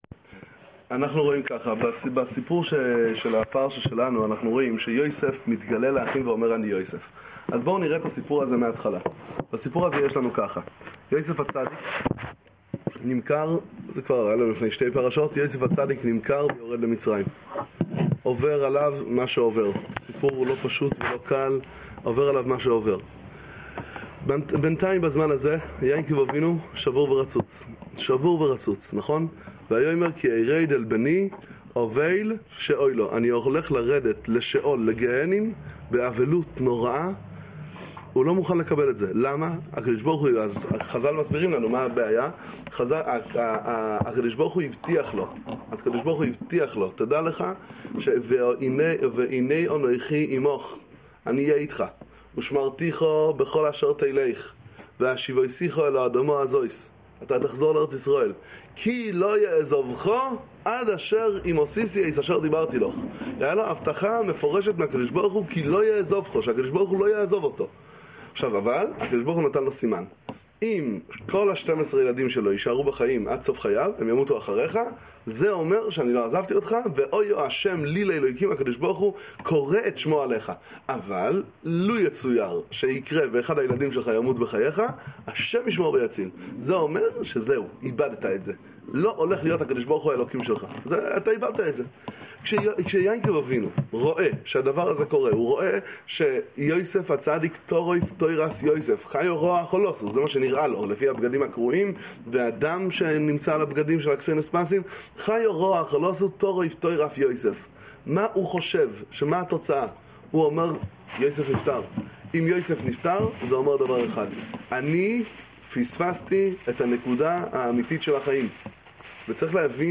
דבר תורה קצר מספר מי השילוח איז'ביצא